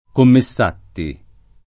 [ komme SS# tti ]